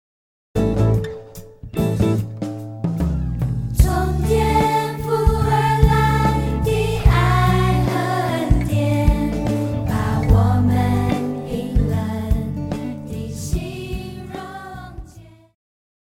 宗教
套鼓(架子鼓)
乐团
童谣,流行音乐,教会音乐
演奏曲
独奏与伴奏
有主奏
有节拍器